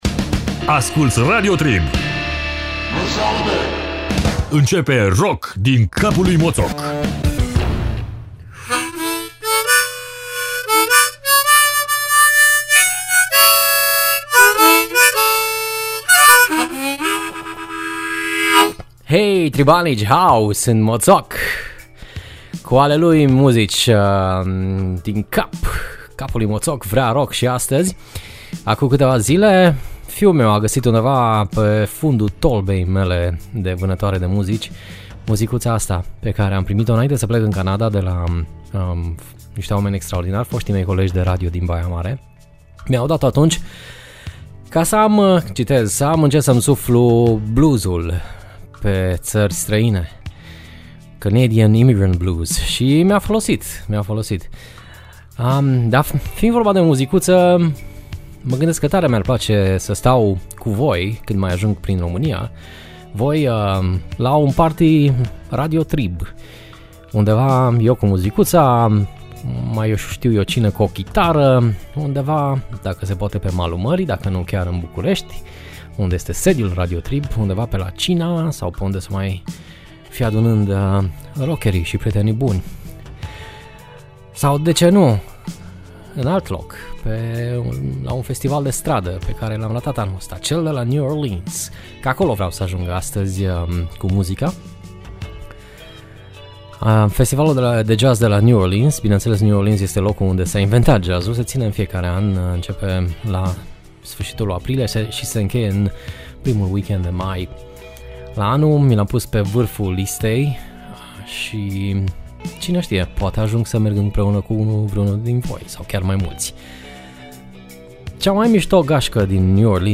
“Poluarea” ce se aude pe prima piesa e cu onor al dumneavoastra DJ, suflind cu ardoare in muzicuta.